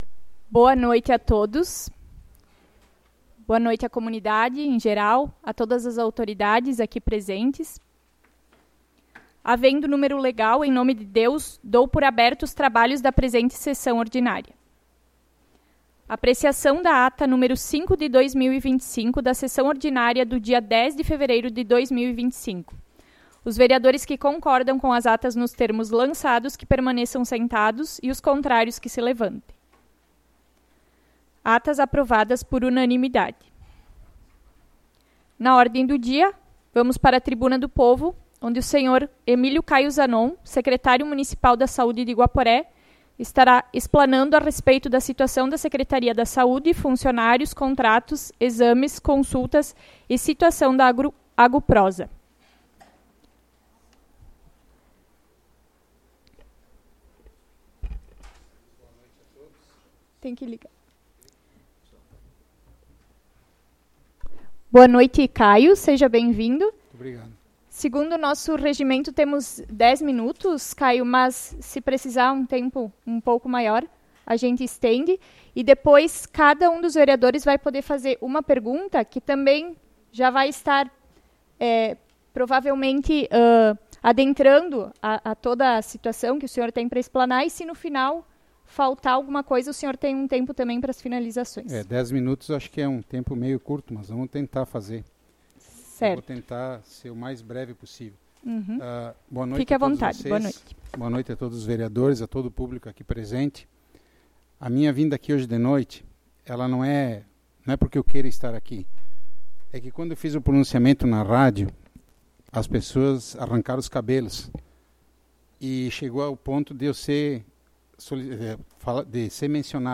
Sessão Ordinária do dia 24 de Fevereiro de 2025